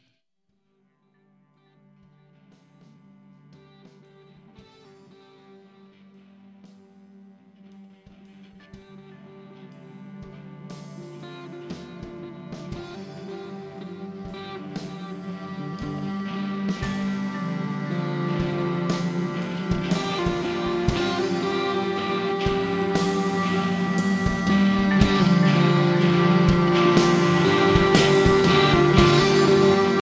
Psicadélia exacerbada, sem pretensões.